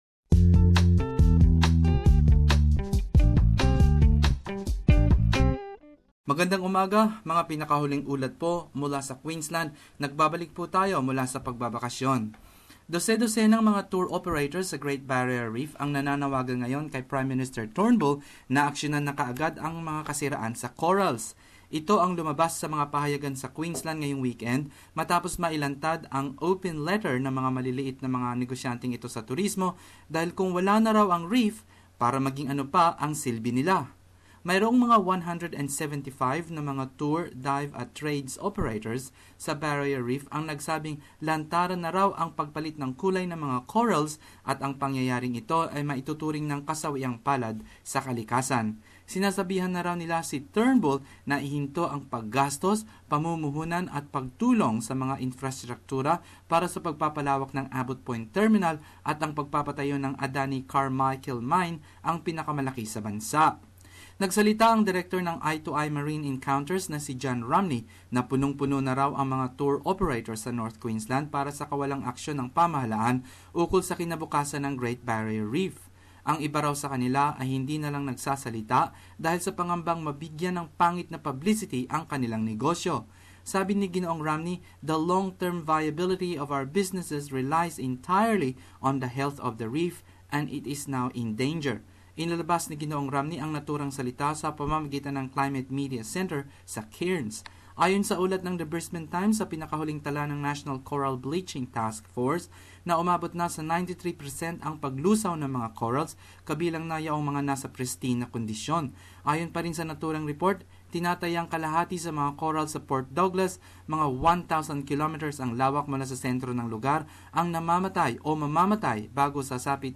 Queensland News.